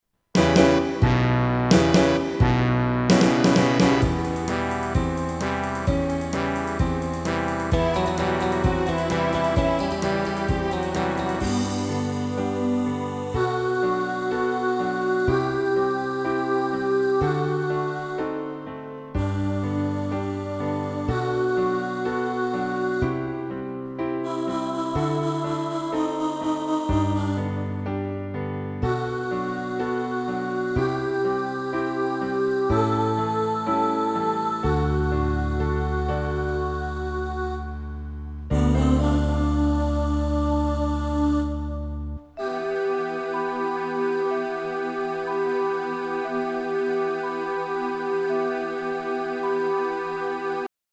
Live And Let Die (Alto) | Ipswich Hospital Community Choir